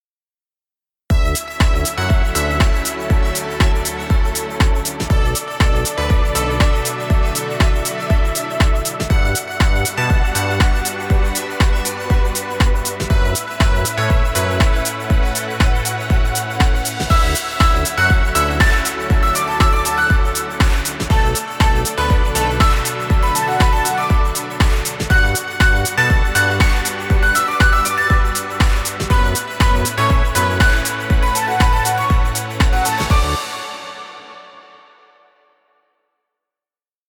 Dance Music for Video.